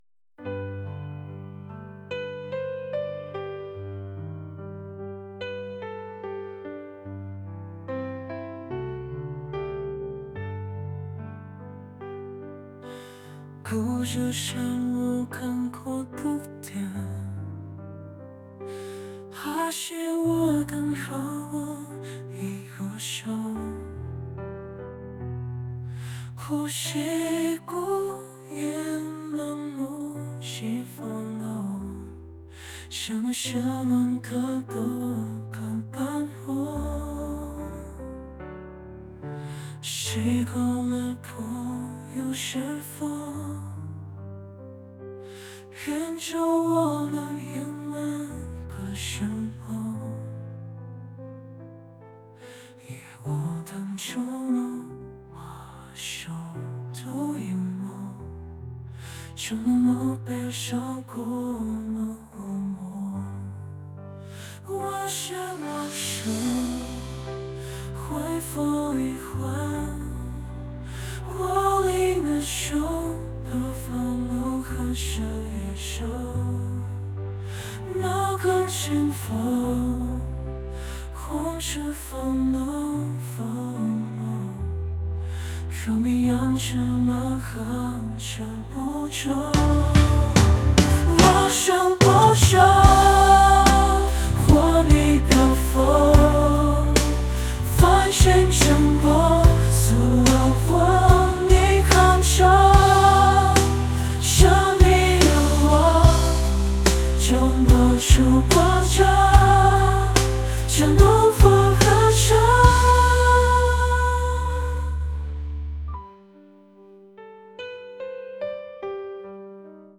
pop | acoustic | folk